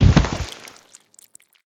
pelt.ogg